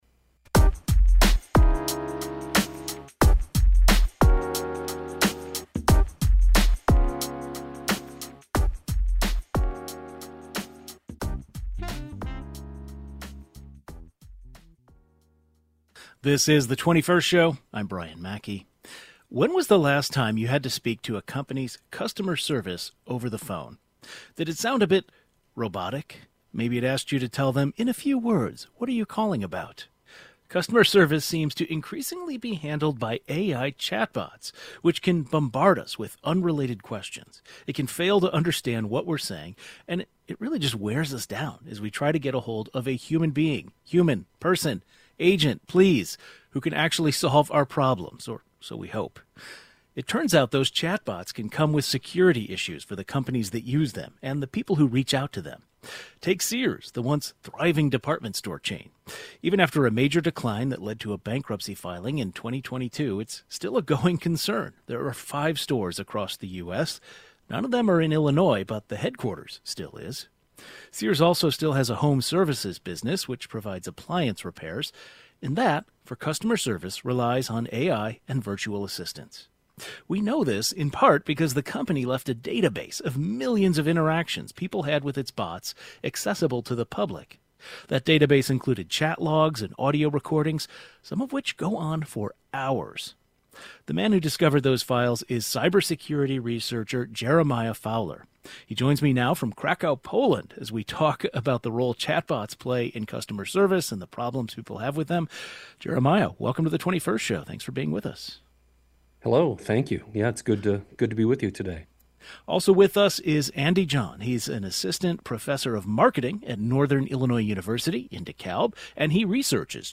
A cybersecurity researcher and a marketing expert share their thoughts.